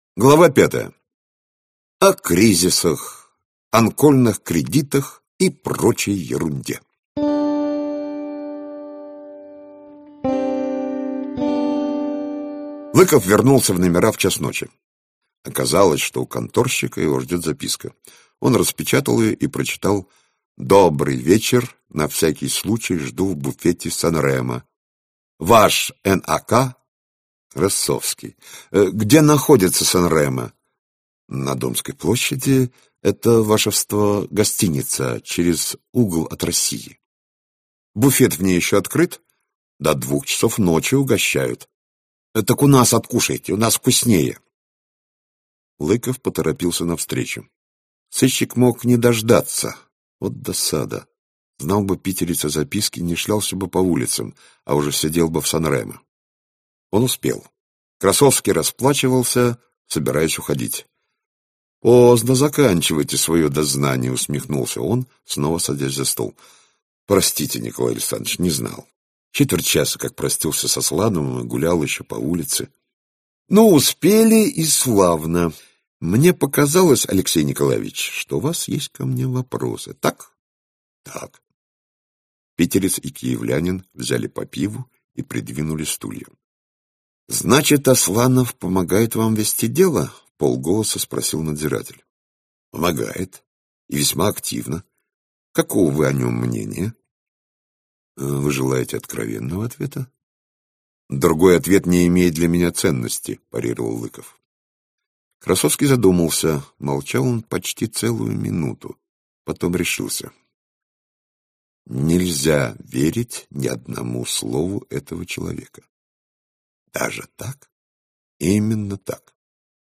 Аудиокнига Касьянов год - купить, скачать и слушать онлайн | КнигоПоиск